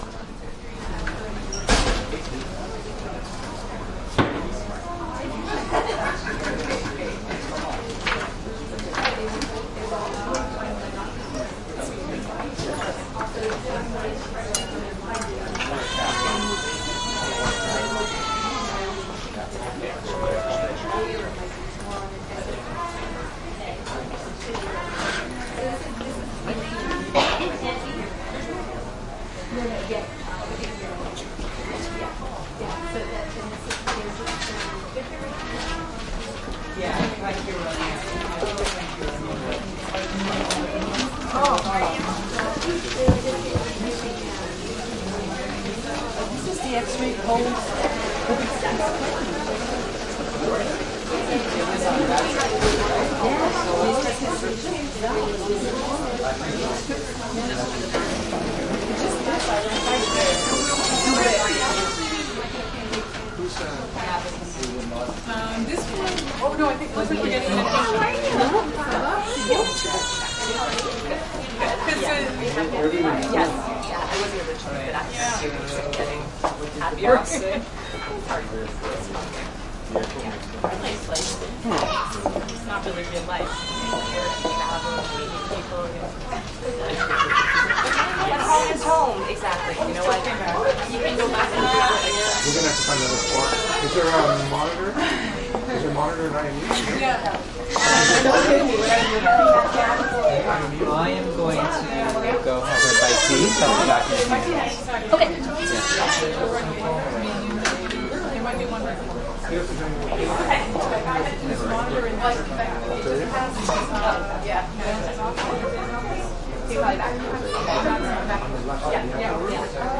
蒙特利尔 " 医院大厅2先忙后静+饮水机噪音
描述：医院hall2忙碌然后安静结束+饮水机噪音蒙特利尔，加拿大
标签： 蒙特利尔 大厅 安静 医院 忙碌 加拿大
声道立体声